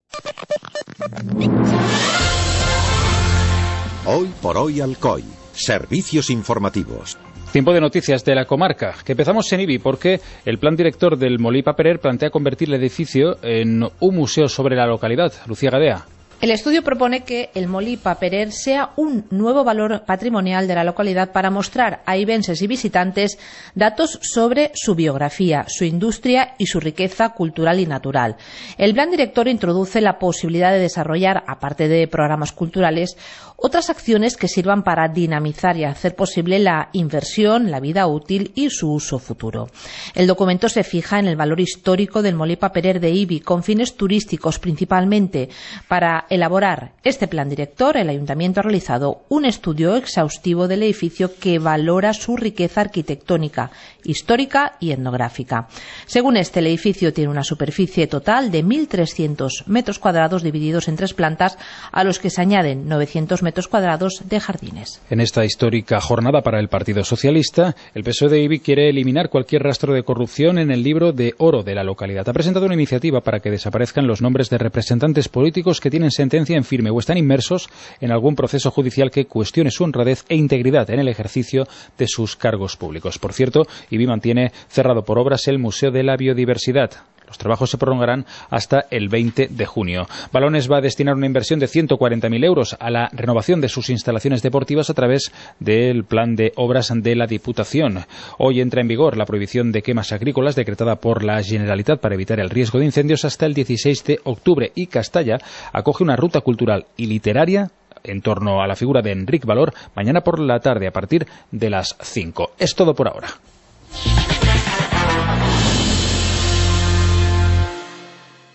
Informativo comarcal - viernes, 01 de junio de 2018